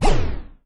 etfx_shoot_energy01.wav